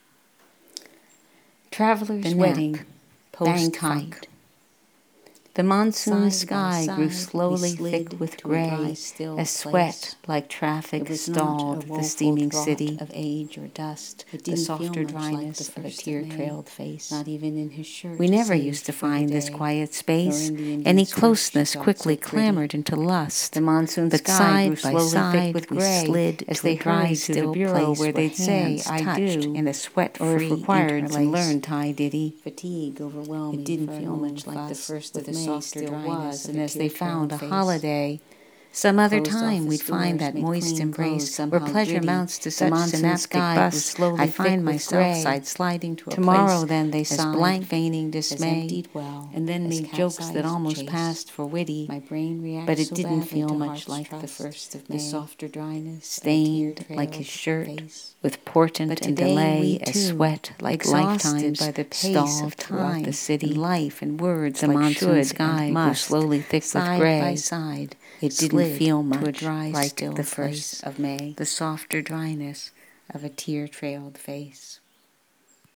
To spice up this villanelle a bit, I have made a doublespeak audio recording of it,  pairing it with another villanelle called “The Nap (Post-Fight)” (whose text may be found here.)   I personally find the mixed audio more intriguing than reading the single villanelle bel0w–there is a really odd music to two villanelles together, that, as incomprehensible as the words are, kind of transcends the texts –at least my texts!